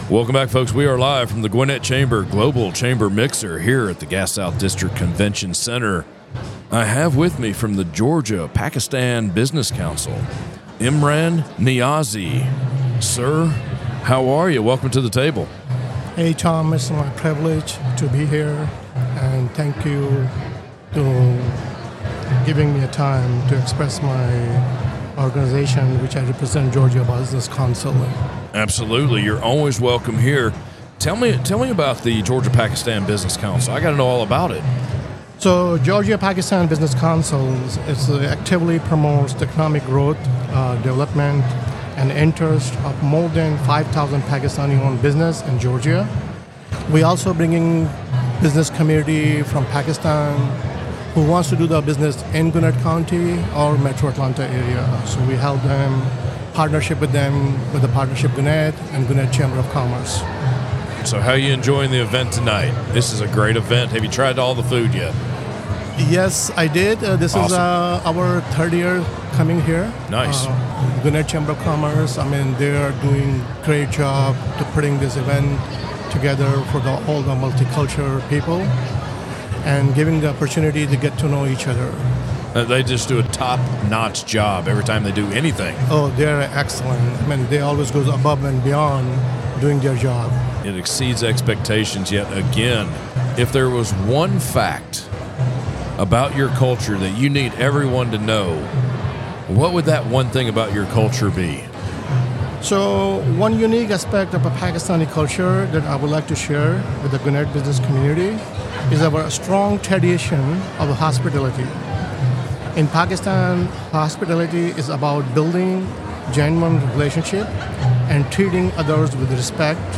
The Gwinnett Chamber of Commerce hosted it’s 2024 Global Chamber Mixer on November 7 at the Gas South Convention Center in Duluth, GA.